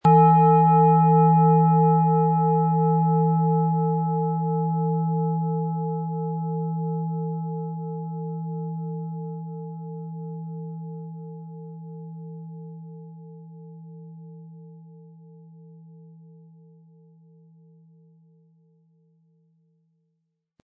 Durch die traditionsreiche Herstellung hat die Schale stattdessen diesen einmaligen Ton und das besondere, bewegende Schwingen der traditionellen Handarbeit.
Mit einem sanften Anspiel "zaubern" Sie aus der Thetawelle mit dem beigelegten Klöppel harmonische Töne.
MaterialBronze